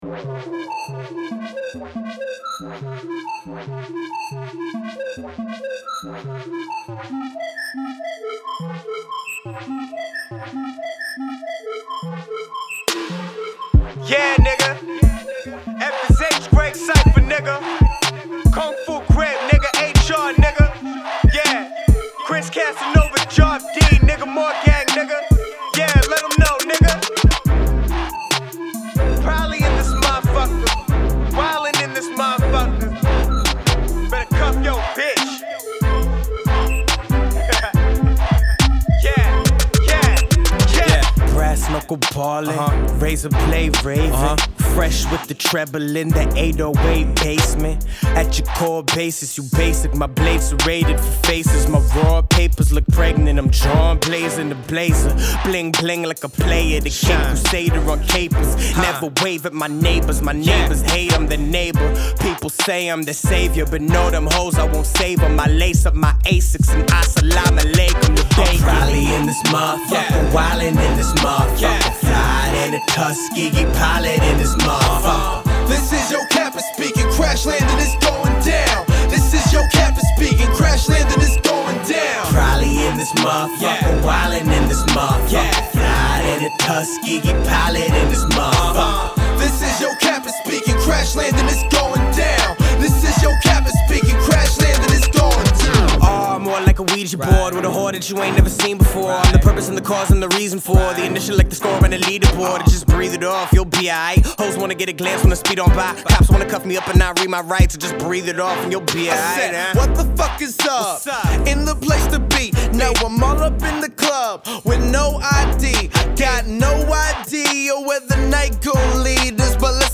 high-strung brag rap